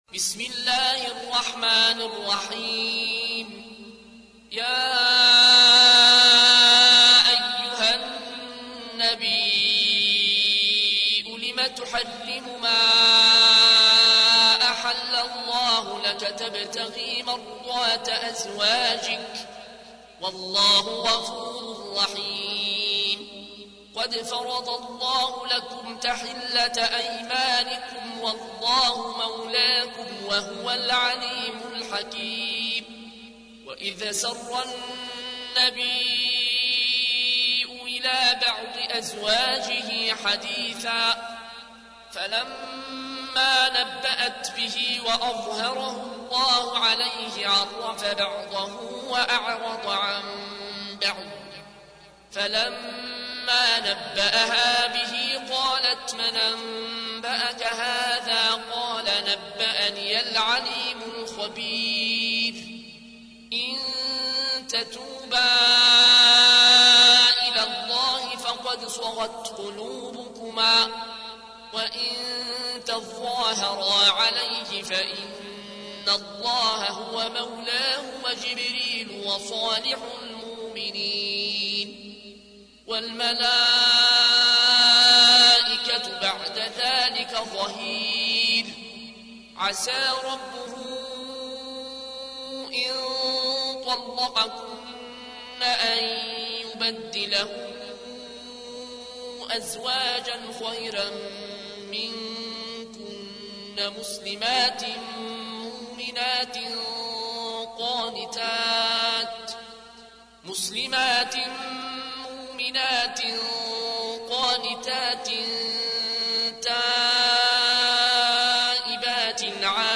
تحميل : 66. سورة التحريم / القارئ العيون الكوشي / القرآن الكريم / موقع يا حسين